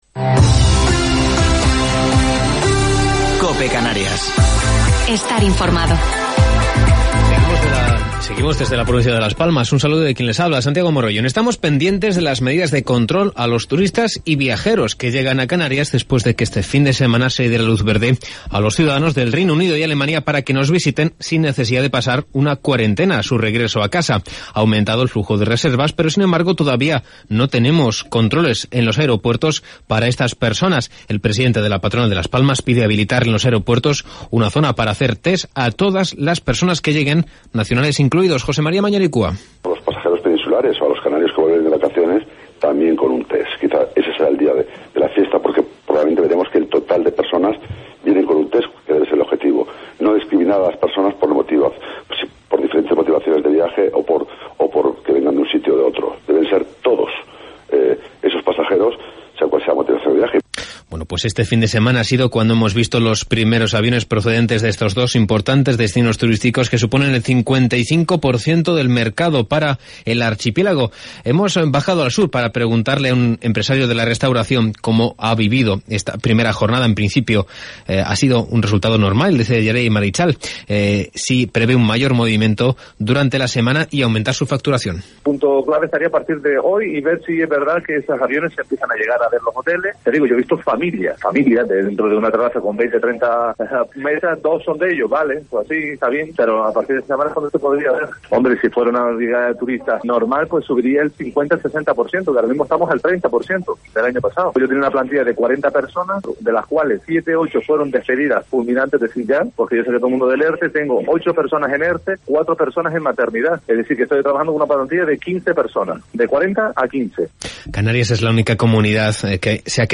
Informativo local 26 de Octubre del 2020